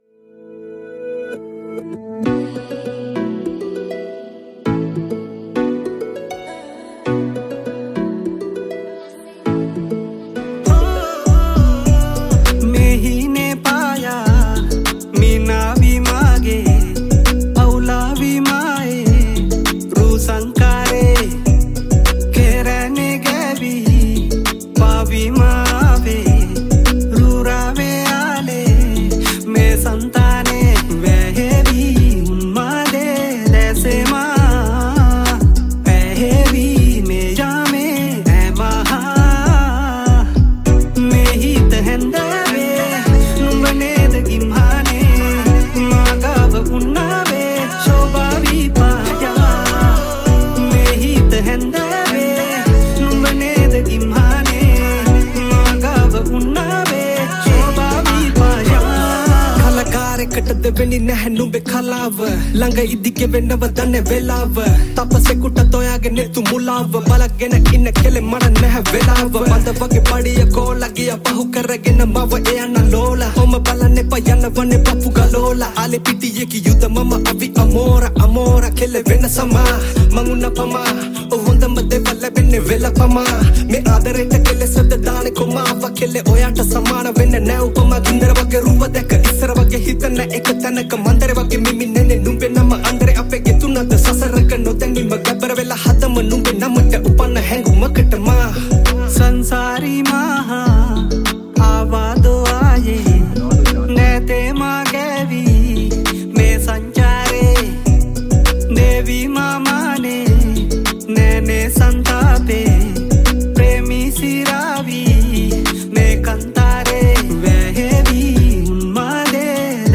Keyboards
Category: Rap Songs